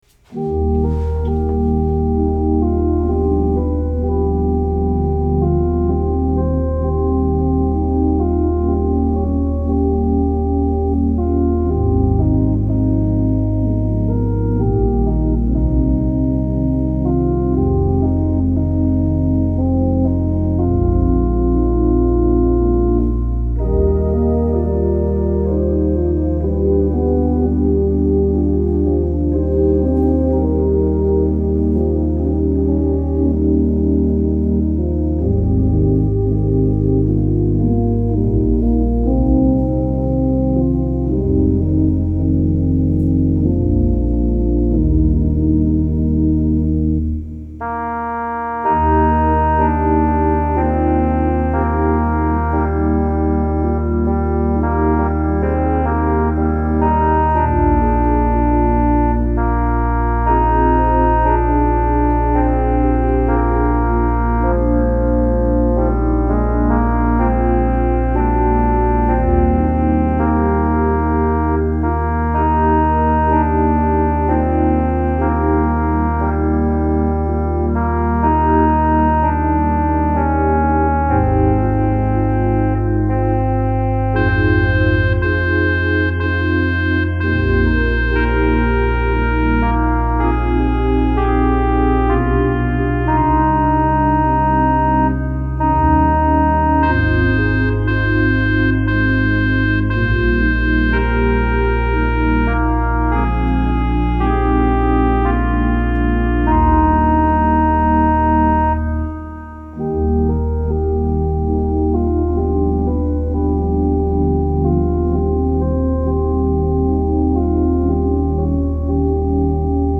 I hope you enjoy this prelude.